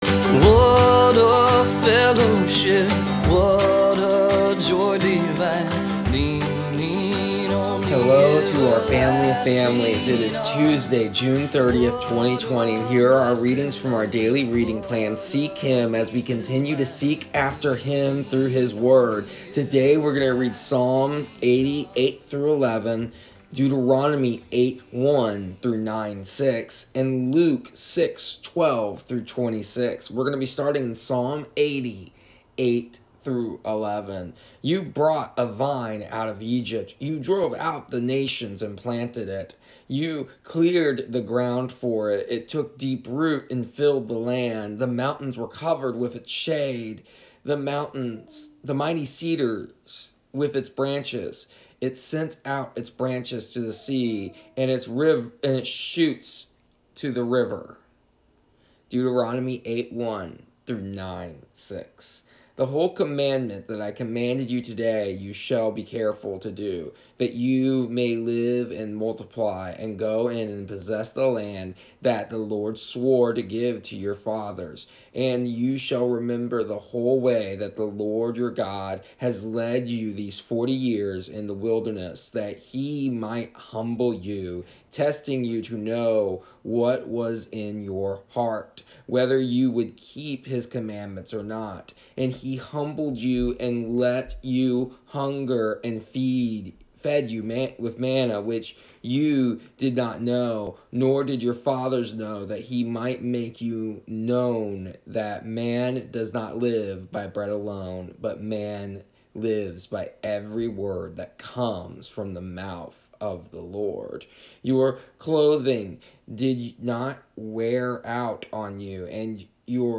Here is an audio version of our daily readings from our daily reading plan Seek Him for June 30th, 2020. Today we discuss how God is the one who gives us our righteousness. How God is the one who achieves our salvation.